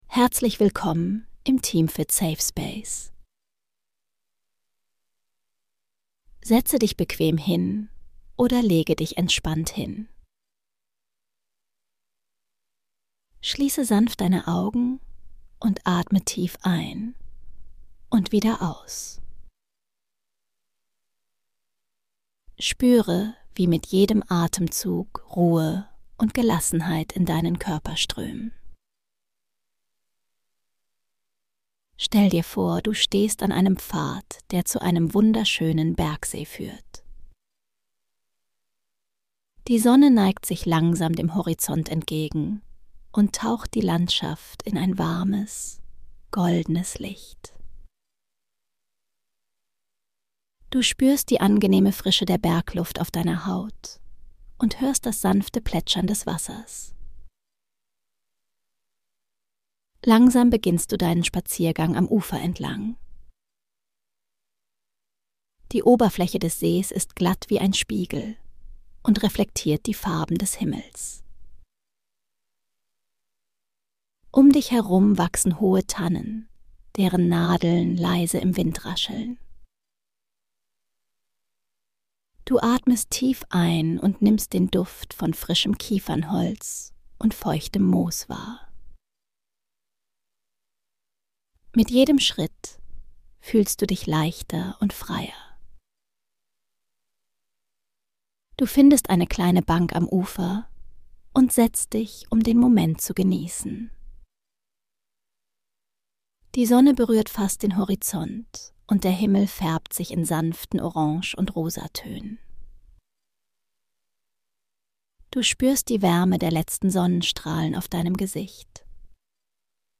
Eine beruhigende Traumreise zu einem friedlichen Bergsee bei